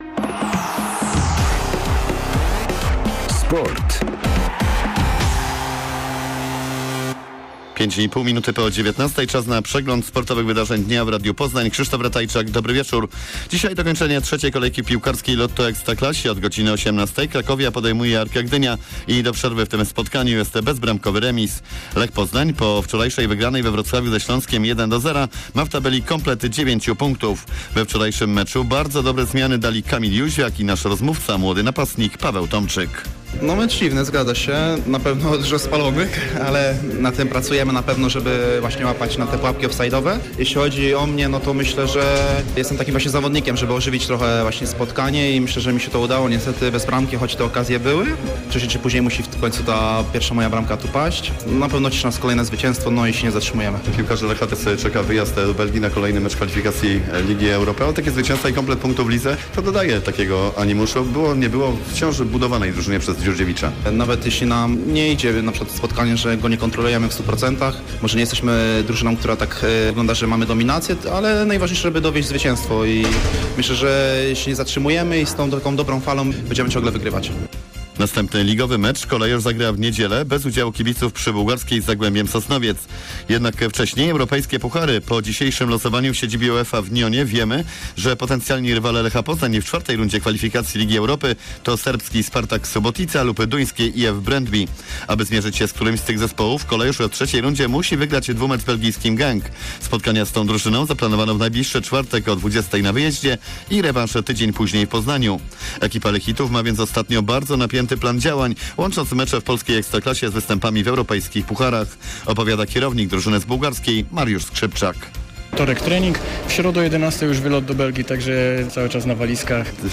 06.08 serwis sportowy godz. 19:05